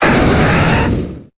Cri de Galopa dans Pokémon Épée et Bouclier.